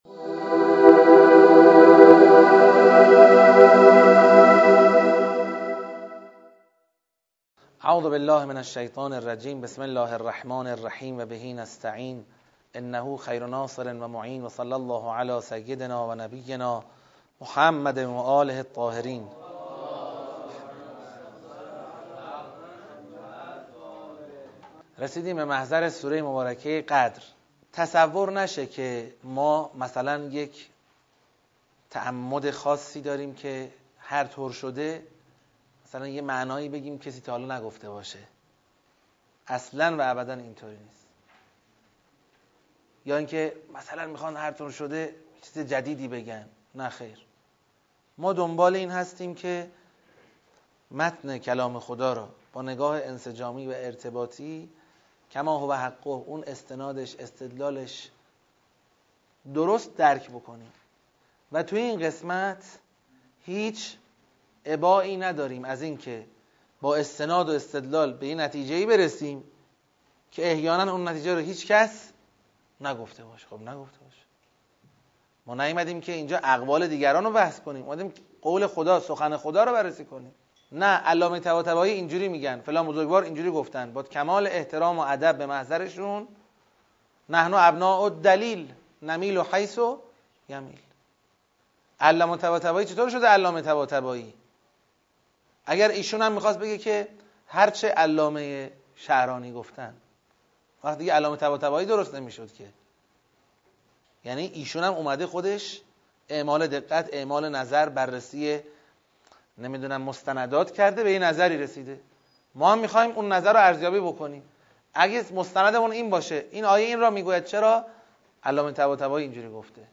آموزش تدبر در قرآن ویژه طلاب – سوره قدر (فایل 27 از سطح ۱)